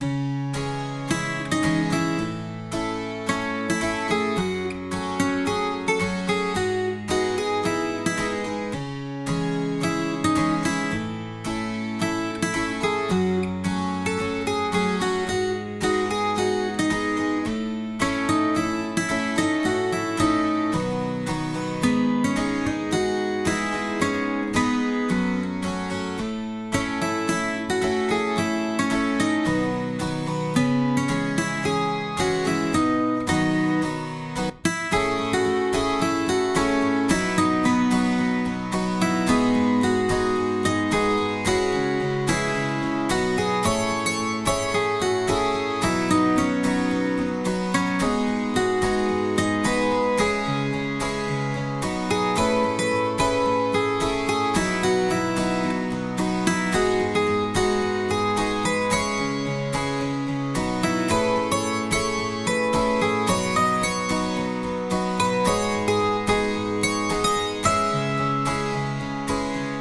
Пожелания те же, хук в куплете, двухоктавная (полуторнооктавная, ок) мелодия ...